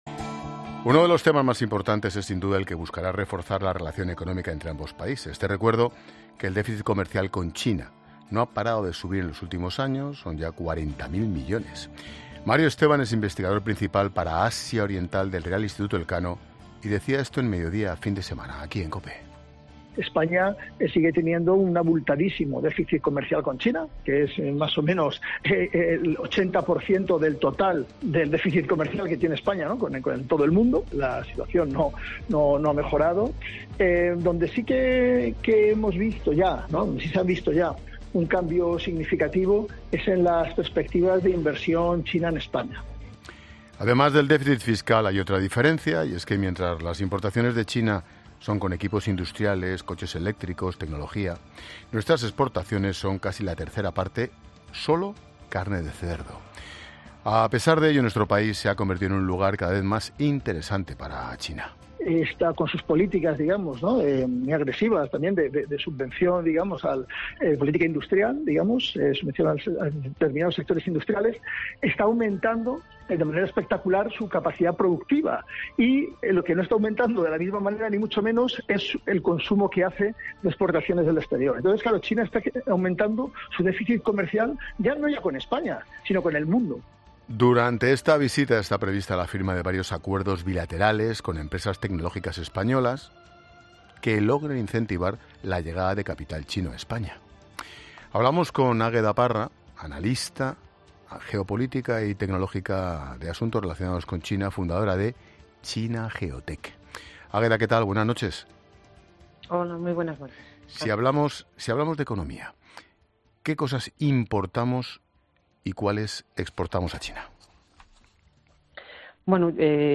Este nuevo encuentro, que incluye reuniones con inversores chinos y con su homólogo, Xi Jinping , es analizado en el programa ' La Linterna ' de COPE con Ángel Expósito , donde los expertos desgranan las oportunidades y los grandes desafíos de una relación marcada por un creciente desequilibrio comercial.